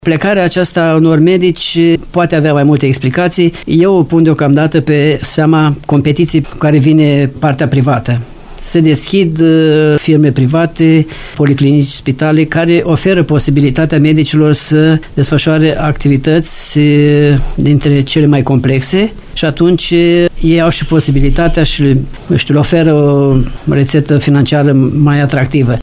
NICULAI BARBĂ a declarat postului nostru că Spitalul Județean poate compensa partea salarială cu asigurarea locuințelor de serviciu și cu investiții în aparatură medicală.